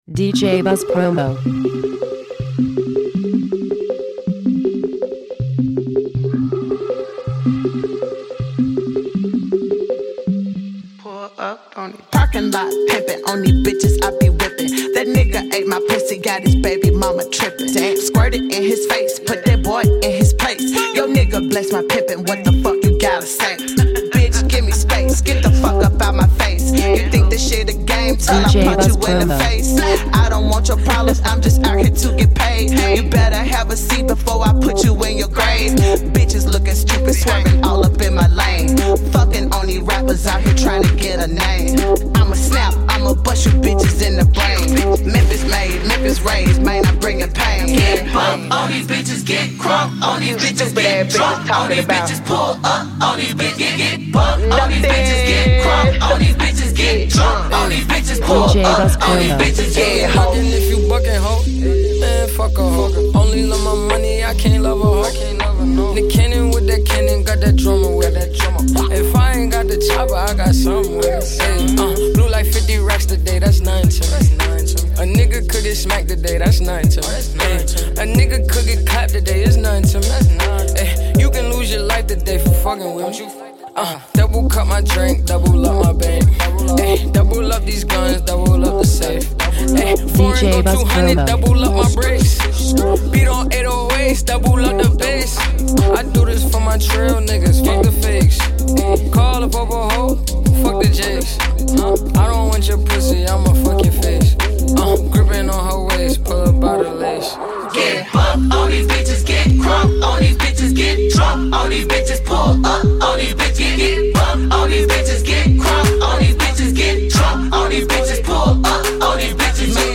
Future Hip Hop!
Original Mix